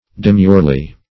Demurely \De*mure"ly\, adv.